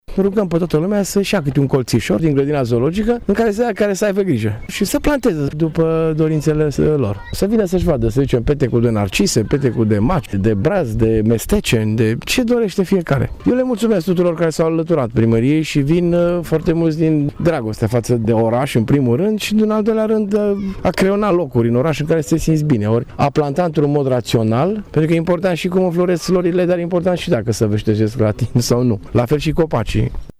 Primarul Dorin Florea a invitat cetățenii să-și aleagă locurile de la Platoul Cornești pe care doresc să le planteze: